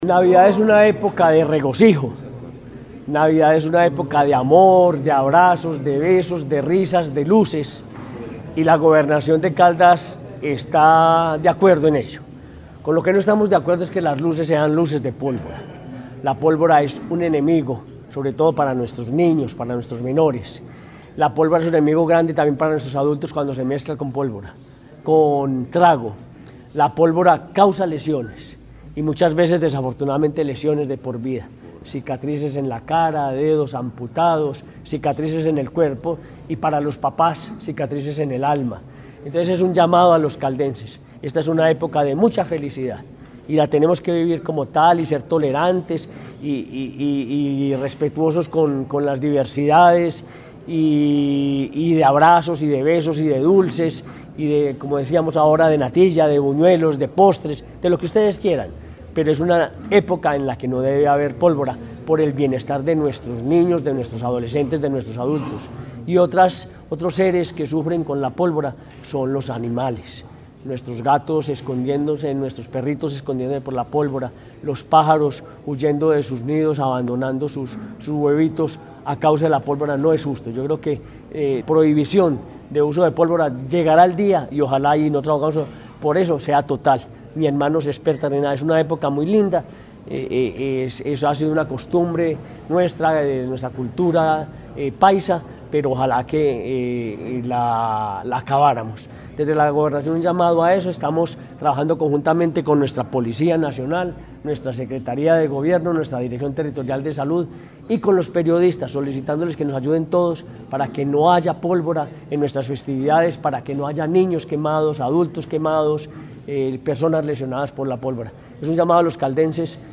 La Gobernación de Caldas, en articulación con la Dirección Territorial de Salud de Caldas (DTSC), el Instituto Colombiano de Bienestar Familiar (ICBF) y la Policía Nacional, realizó el lanzamiento oficial de la campaña departamental de prevención del uso de pólvora para la temporada 2025.
Henry Gutiérrez Ángel, Gobernador de Caldas.